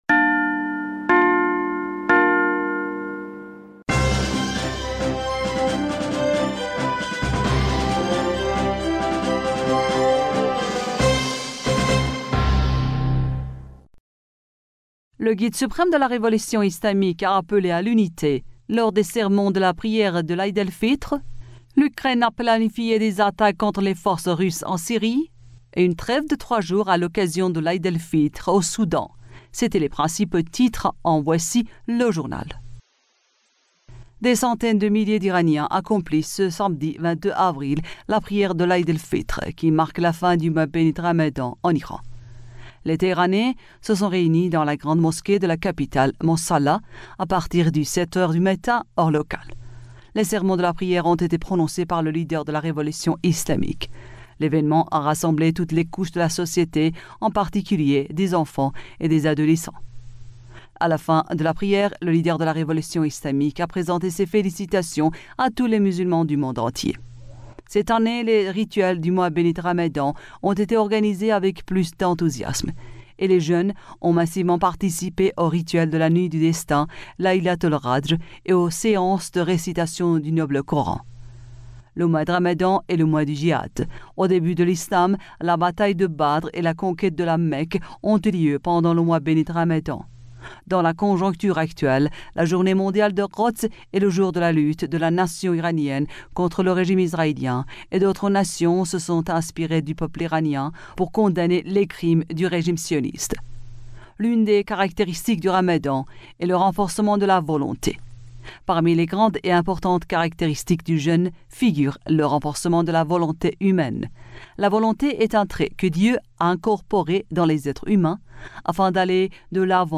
Bulletin d'information du 22 Avril 2023